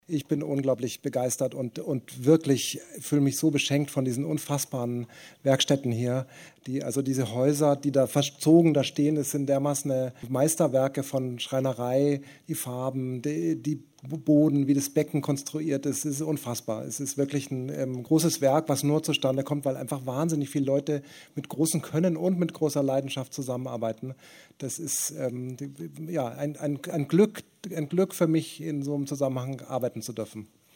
Richtfest "Der Freischütz": Newsmeldung/OT Philipp Stölzl